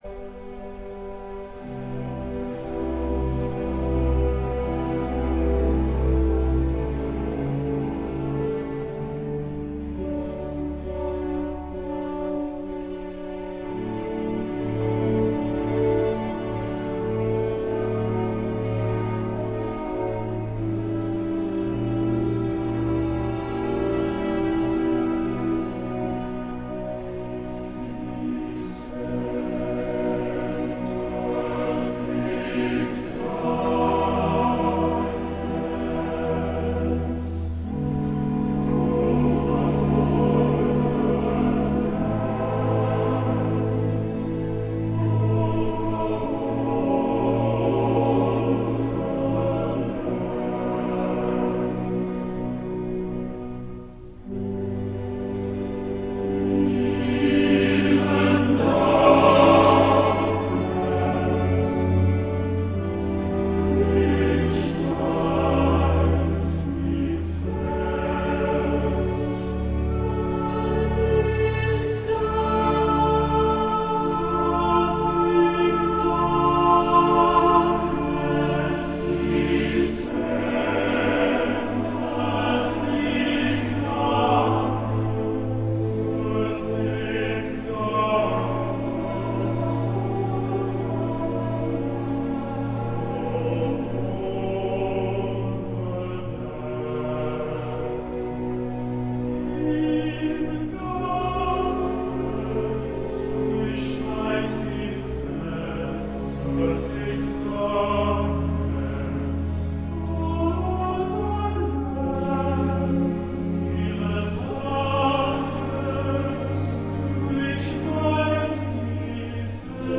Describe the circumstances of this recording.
Here are some samples of great choral music, mostly own recordings made during concerts of the choirs named on this site.